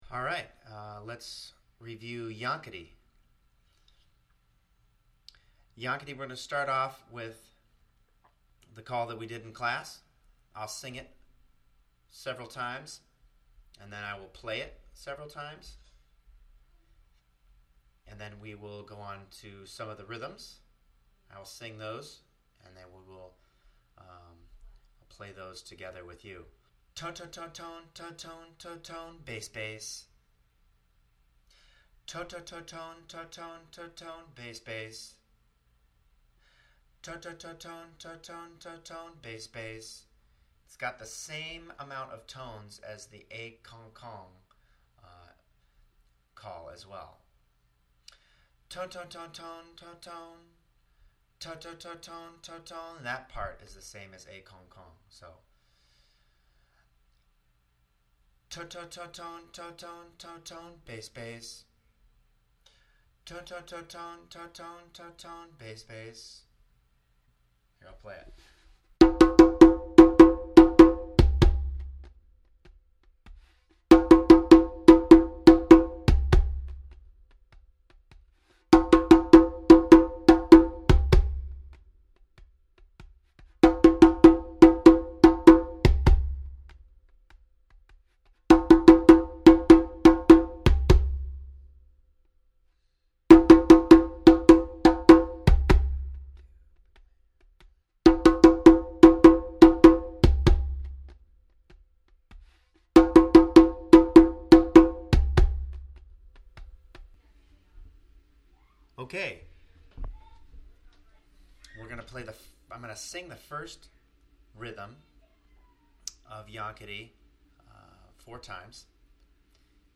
Call & first rhythm (6:00,5.8MB)
yankadi_call_and_rhythm_1.mp3